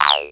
crunk efx 10.wav